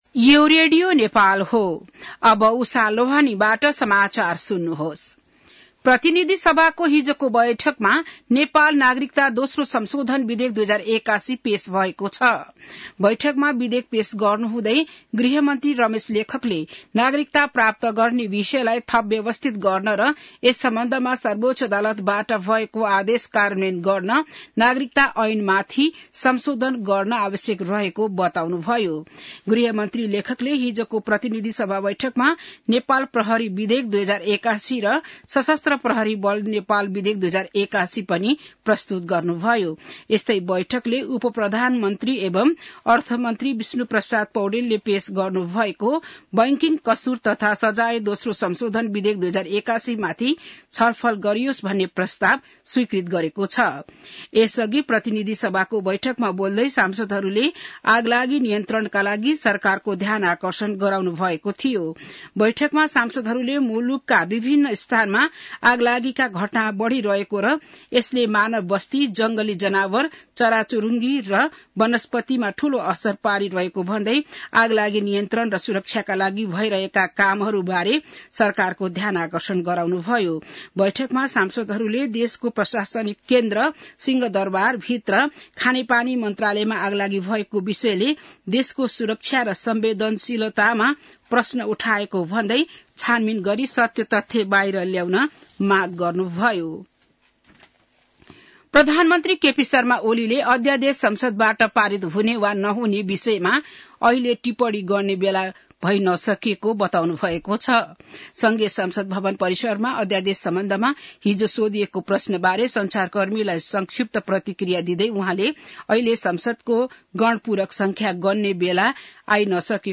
बिहान १० बजेको नेपाली समाचार : २९ माघ , २०८१